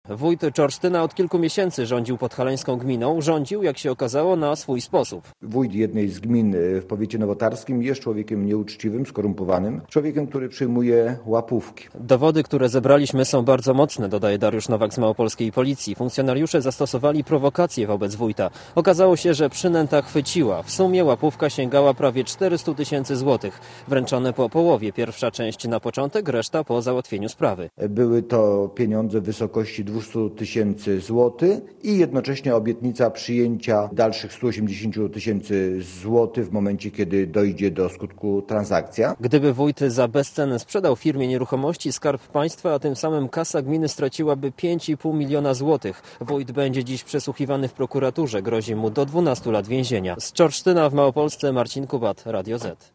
Posłuchaj relacji korespondenta Radia Zet (0,4 MB)